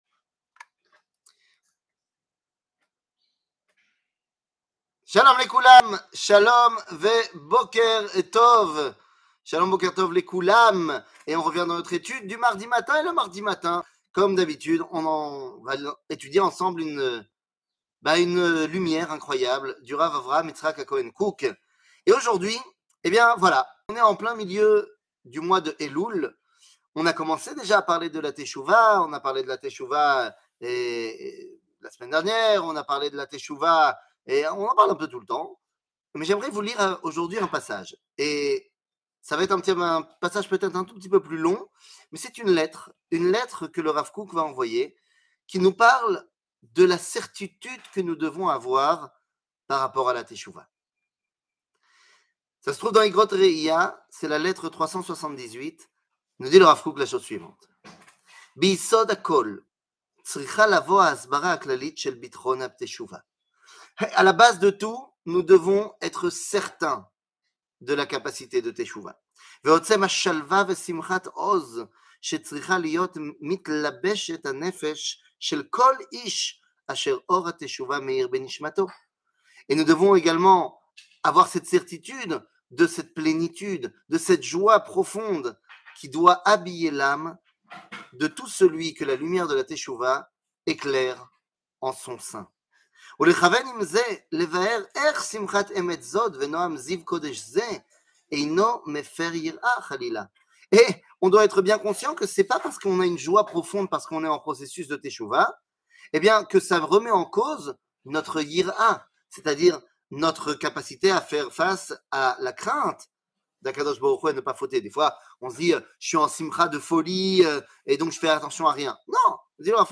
שיעור מ 06 ספטמבר 2022